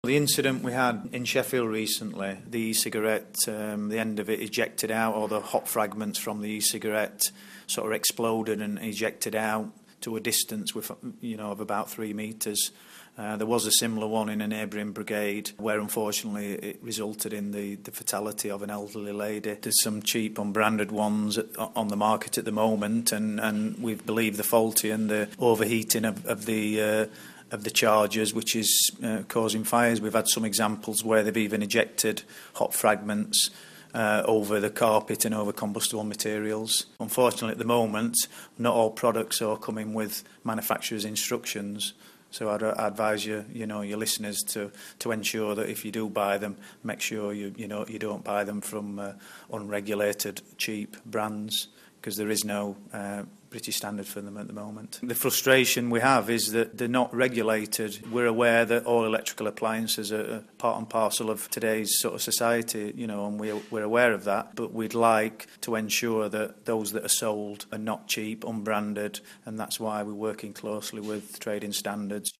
fire spokesperson